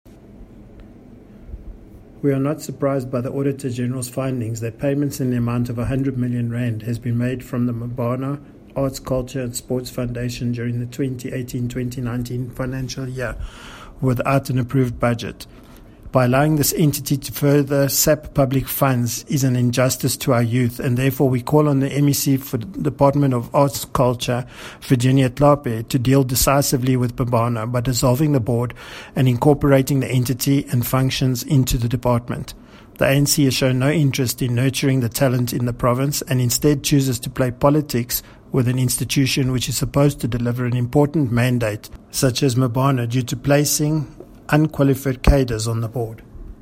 Note to Editors: Kindly find attached soundbites in
Afrikaans by DA North West Spokesperson on Arts and Culture, Gavin Edwards.